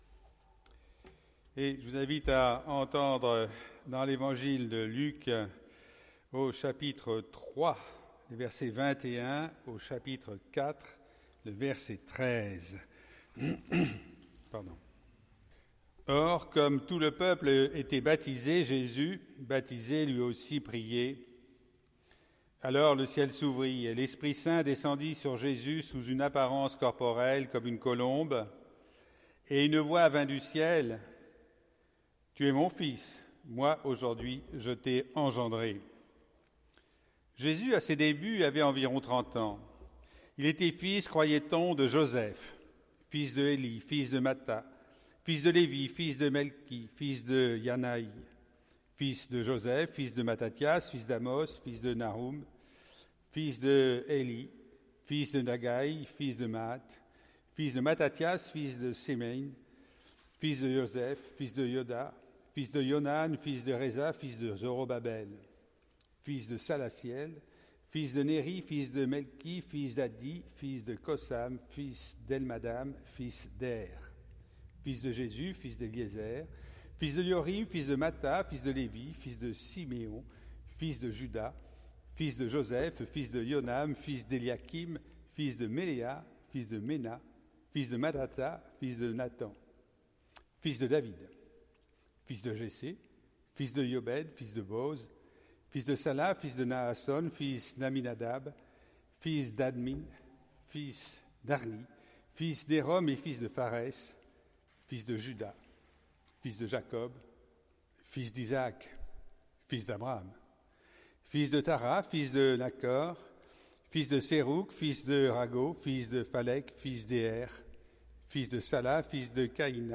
Prédication du 8 avril 2018